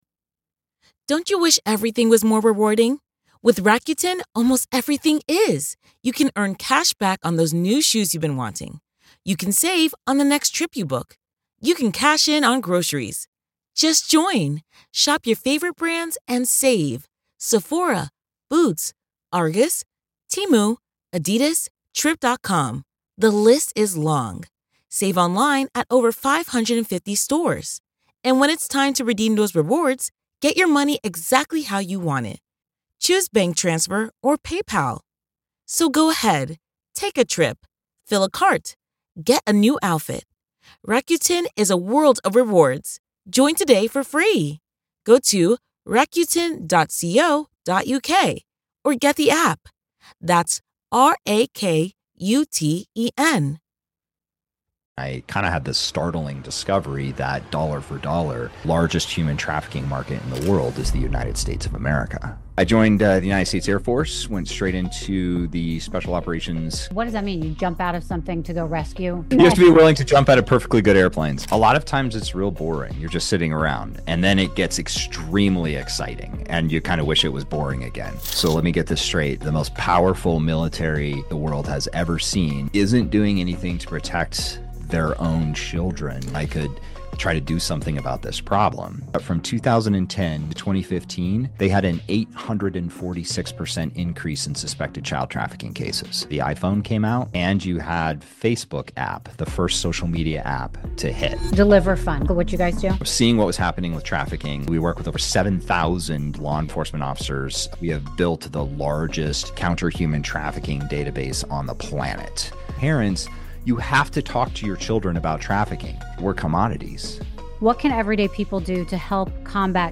This is an eye-opening conversation you won’t want to miss.